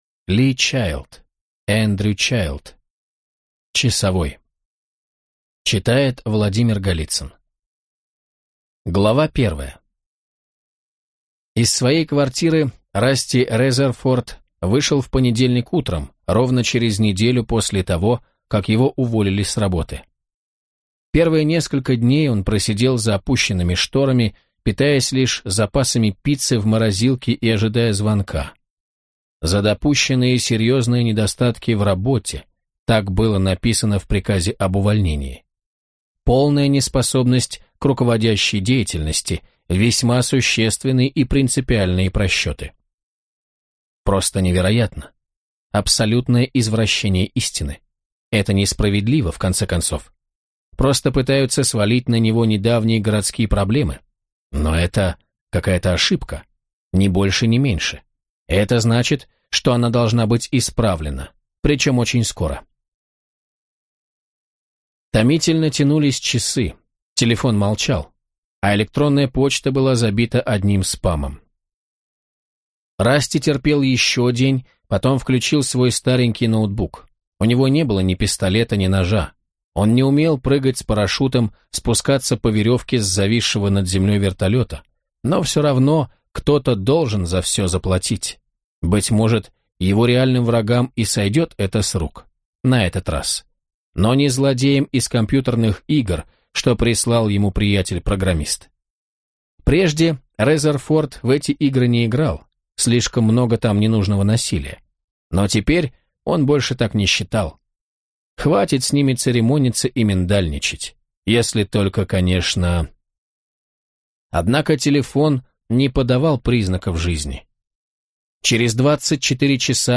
Аудиокнига Джек Ричер: Часовой | Библиотека аудиокниг